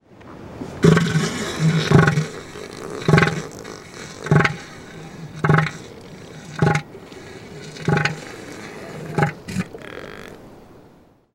На этой странице собраны разнообразные звуки морского слона – от мощного рева самцов до нежных голосов детенышей.
Морской слон издает необычные звуки (на что они похожи?)